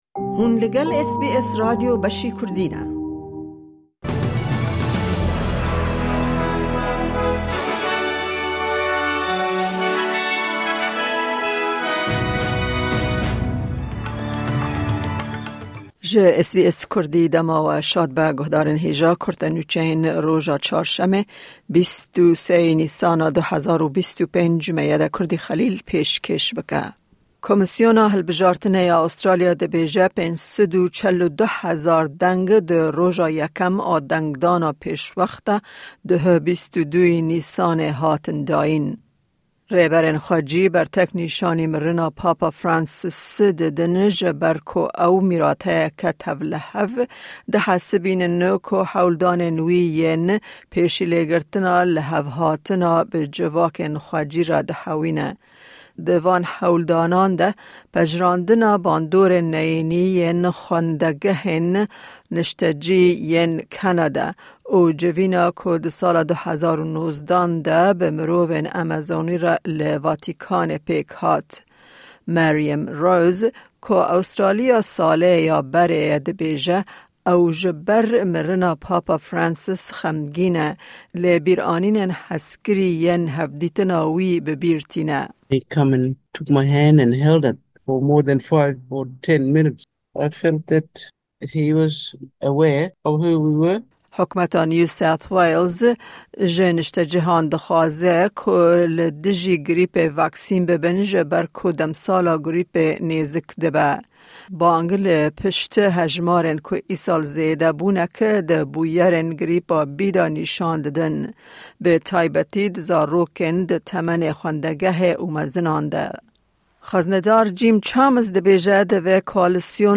Kurte Nûçeyên roja Çarşemê, 23î Nîsana 2025